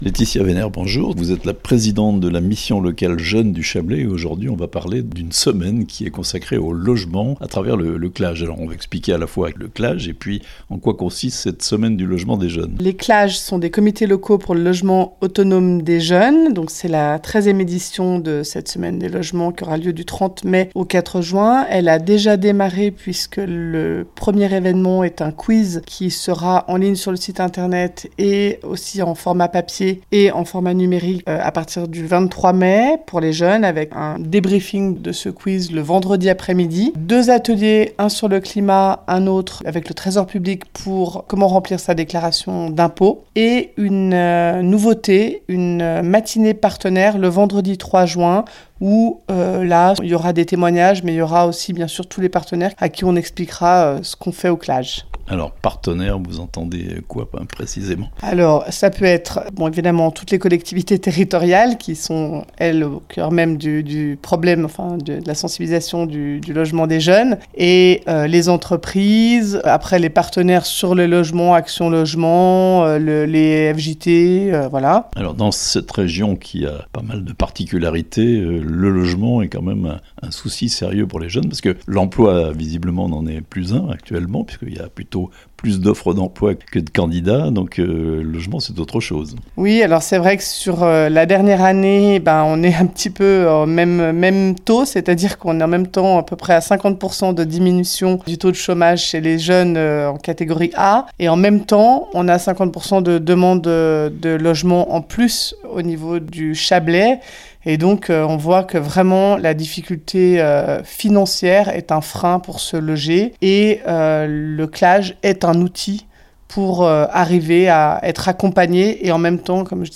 Mobilisation pour le logement des jeunes en Chablais (interviews)